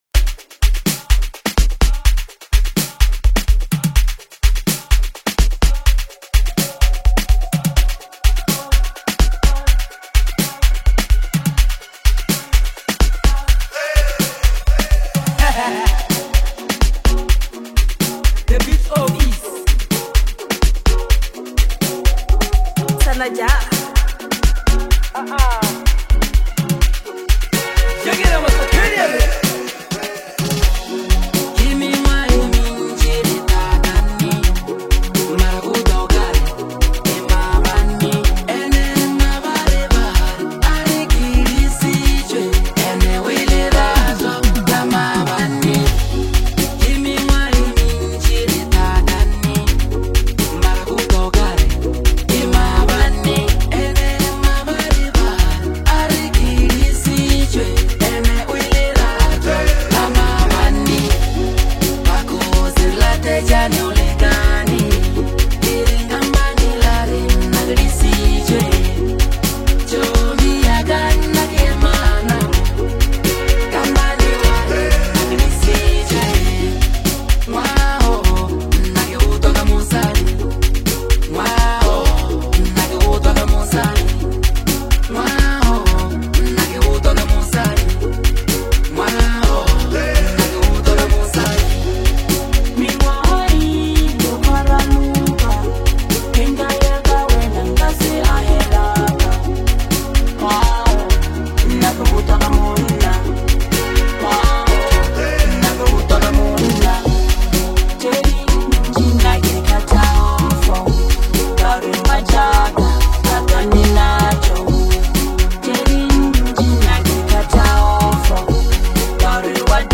deeply expressive and energetic track
an irresistible groove